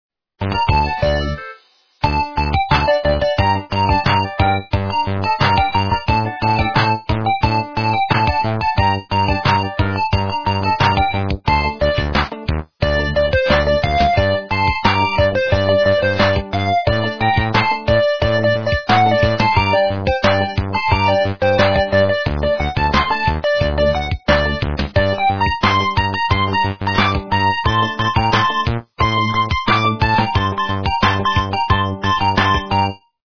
- русская эстрада
полифоническую мелодию